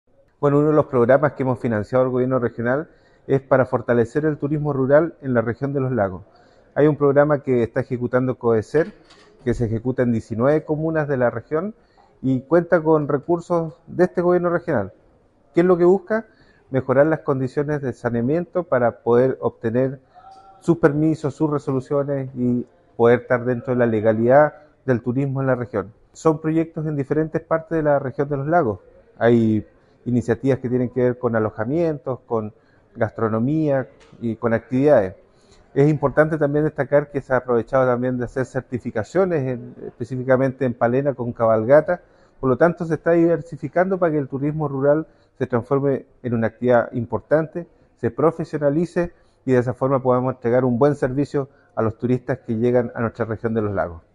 Fernando Hernández, presidente de la Comisión de Fomento Productivo del CORE Los Lagos, sostuvo que este programa tiene como objetivo fortalecer el turismo rural en la Región de Los Lagos, donde entre otras cosas se busca entregar mejores condiciones de saneamiento para poder obtener permisos, sus resoluciones y poder estar dentro de la legalidad del turismo en la región.